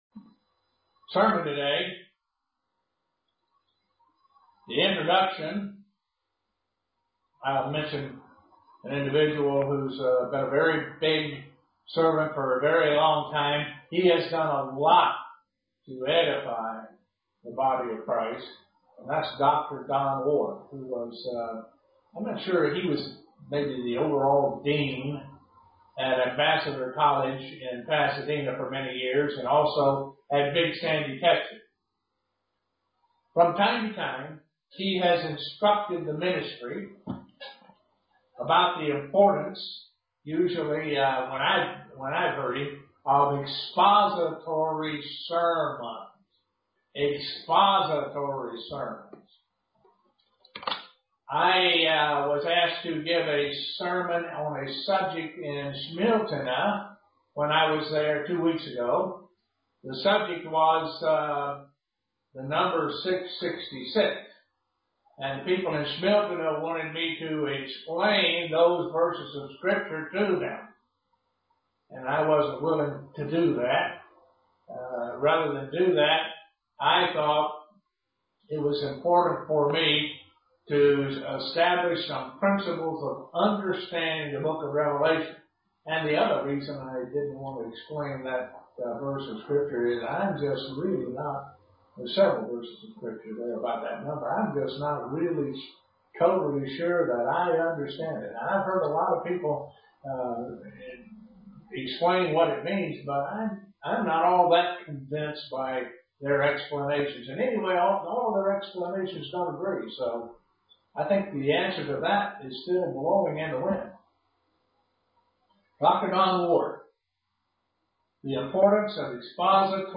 Given in Elmira, NY
Print Explanation of what are three themes of Revelation 1 UCG Sermon Studying the bible?